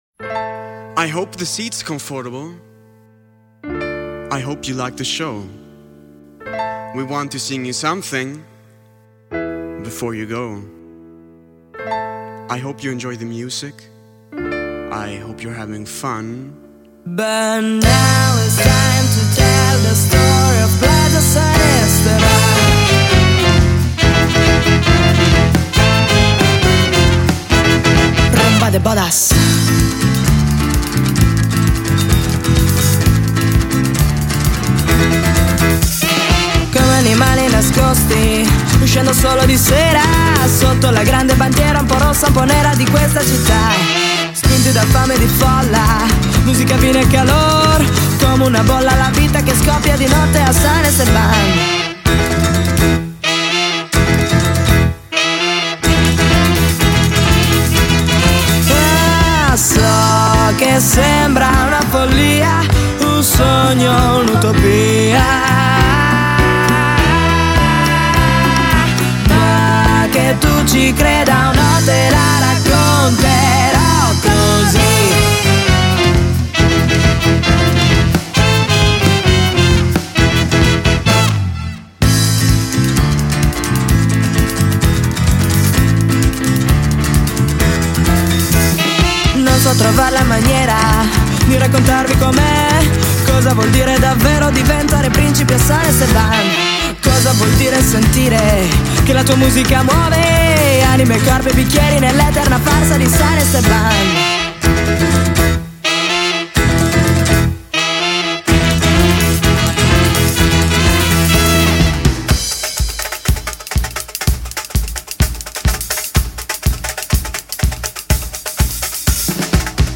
Luogo esecuzioneTeatro Antoniano